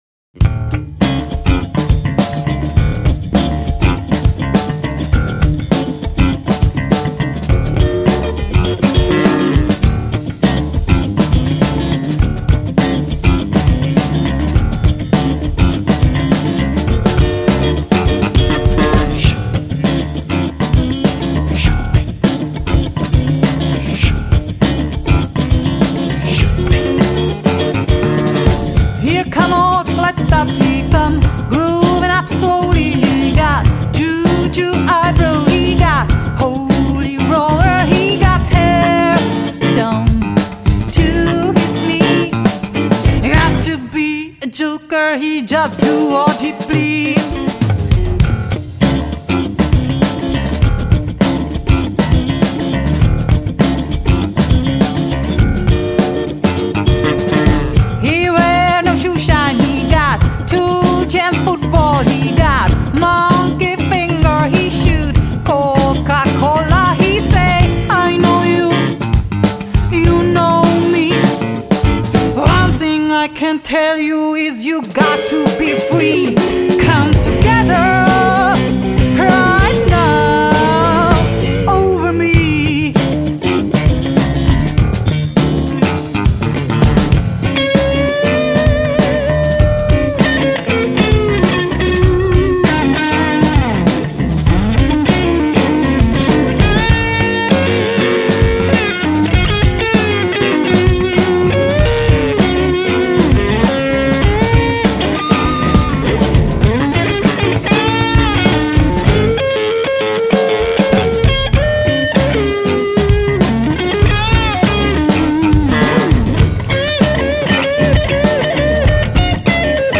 tak ještě dvě věci, je to přemástrovaný
parádní zvuk, hlavně basy.